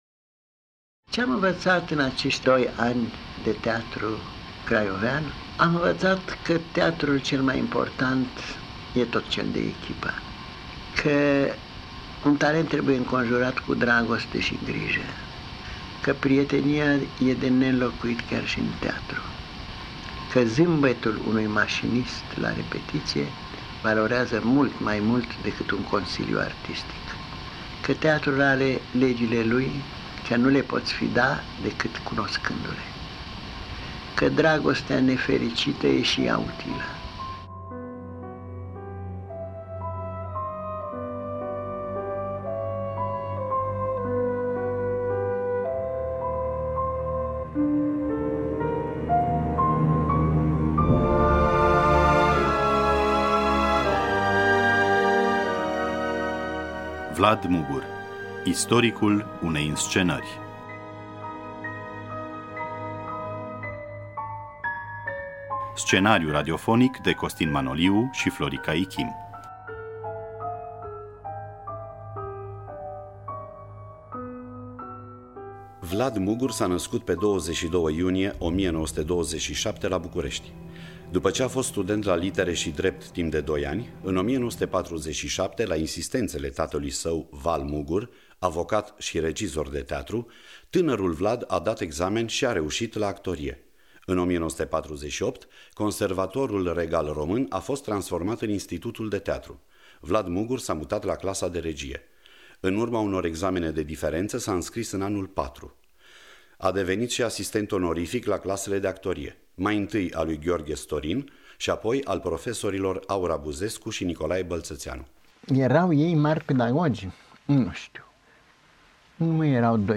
Spectacol de teatru-document bazat pe confesiunile regizorului Vlad Mugur. Cu participarea extraordinară a actorilor: Olga Tudorache, Victor Rebengiuc, Valer Dellakeza, Dumitru Rucăreanu.